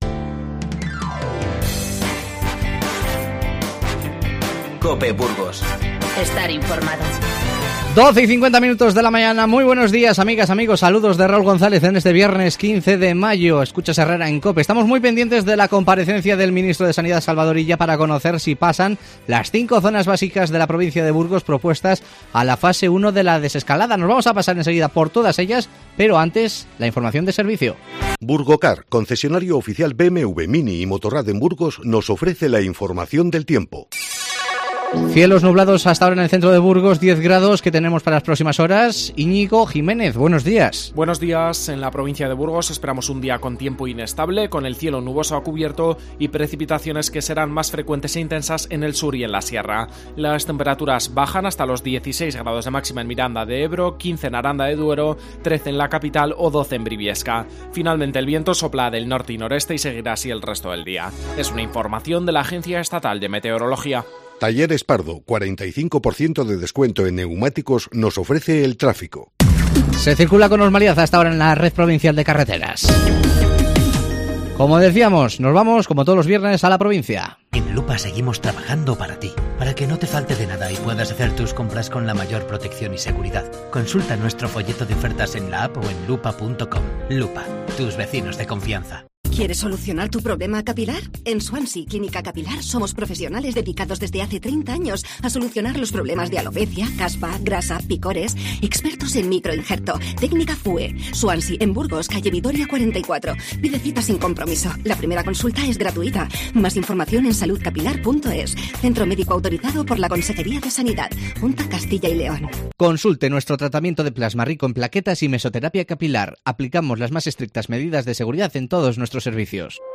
En nuestra sección de la provincia hacemos un repaso por las zonas básicas de salud de Burgos que podrían pasar el lunes a la Fase 1 de la 'desescalada'. Hablamos con los alcaldes de Valle de Valdebezana, Huerta de Rey y Melgar de Fernamental.